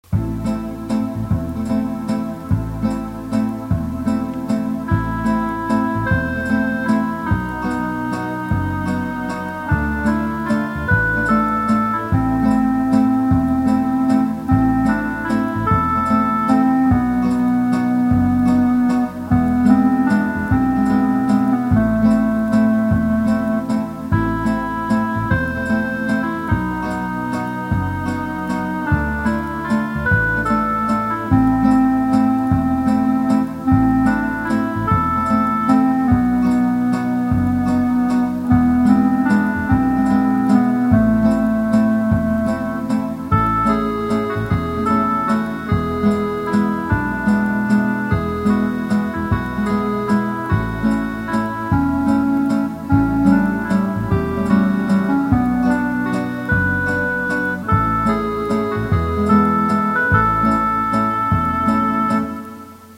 Это вальс.